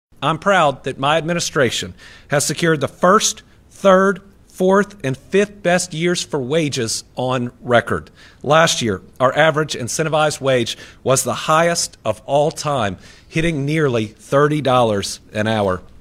The governor says numbers tell the story.  Andy Beshear is reporting on the state’s average incentivized hourly wage.
andy-beshear-0227a.mp3